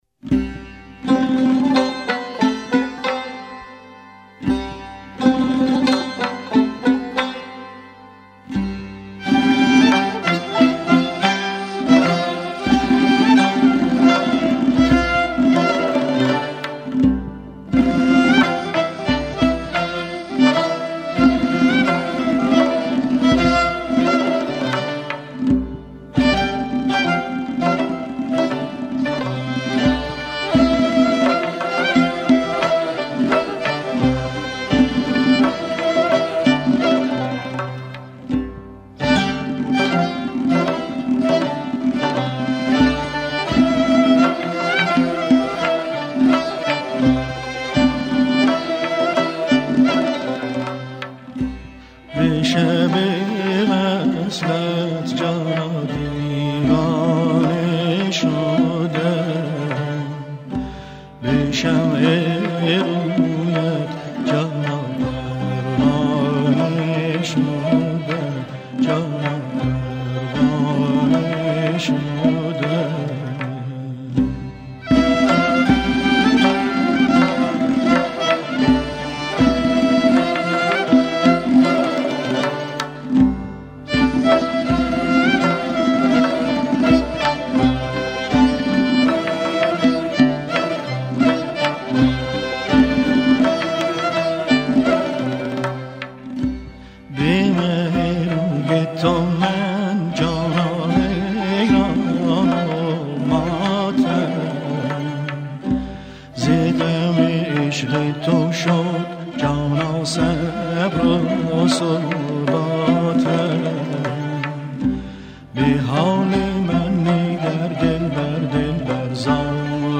اهنگ سنتی